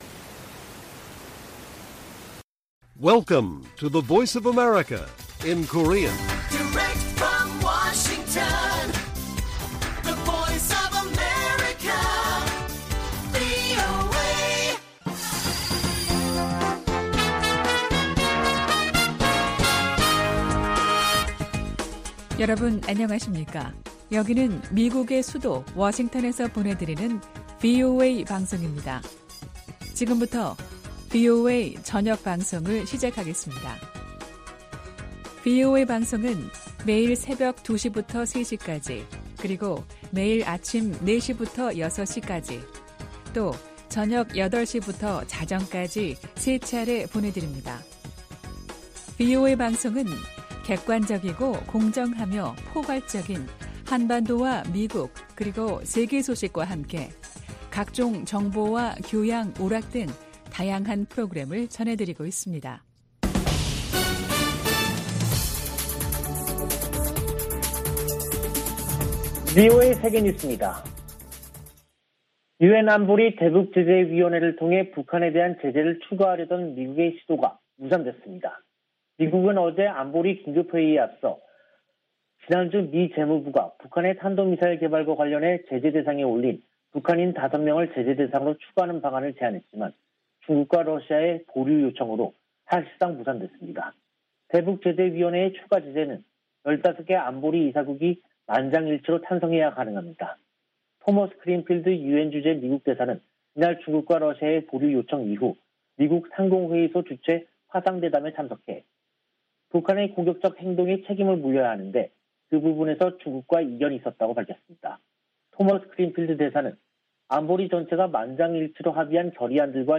VOA 한국어 간판 뉴스 프로그램 '뉴스 투데이', 2022년 1월 21일 1부 방송입니다. 미국 등 8개국이 북한의 탄도미사일 발사를 전 세계에 대한 위협으로 규정하고 유엔에서 추가 제재를 촉구했습니다. 백악관은 북한이 무기 시험 유예를 해제할 수 있다는 뜻을 밝힌 데 대해, 대량살상무기 개발을 막을 것이라고 강조했습니다. 북한이 선대 지도자들의 생일을 앞두고 열병식을 준비하는 동향이 포착됐습니다.